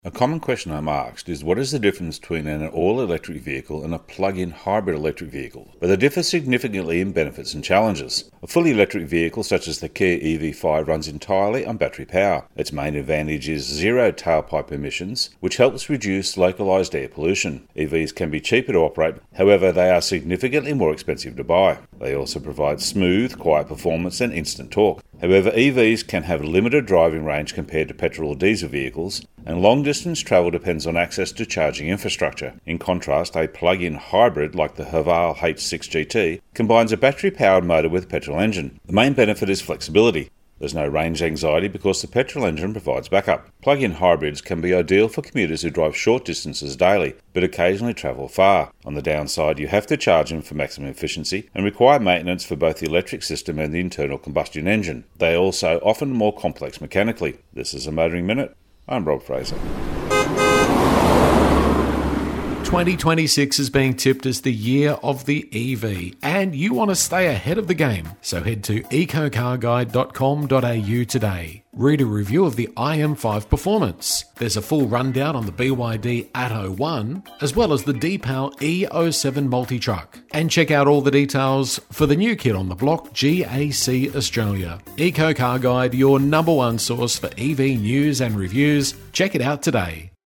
Radio Segments Motoring Minute